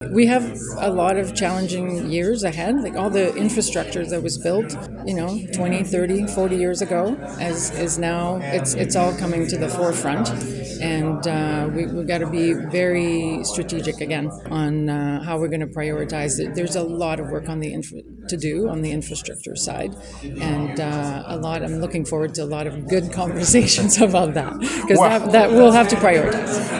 In an interview with myFM afterward, she emphasized the importance of thoughtful leadership on key priorities and promised a year of stability and clarity.